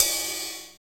CYM RIDE26.wav